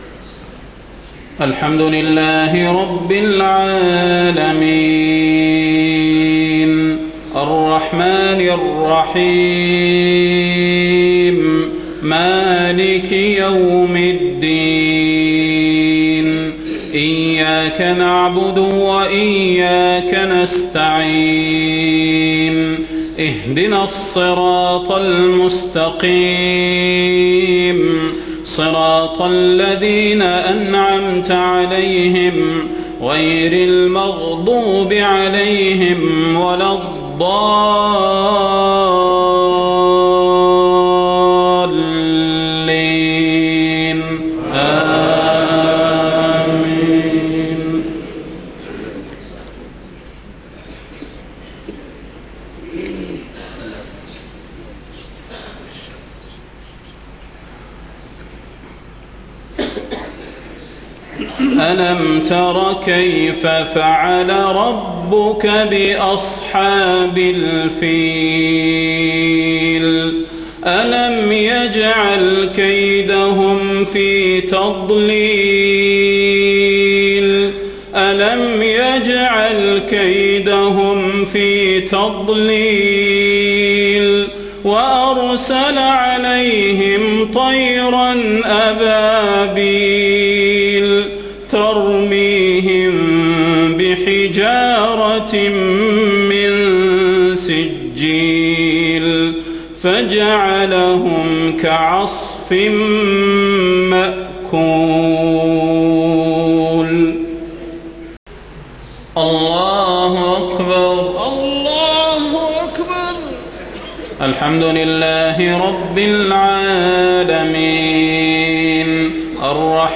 صلاة المغرب 7 محرم 1430هـ سورتي الفيل و قريش > 1430 🕌 > الفروض - تلاوات الحرمين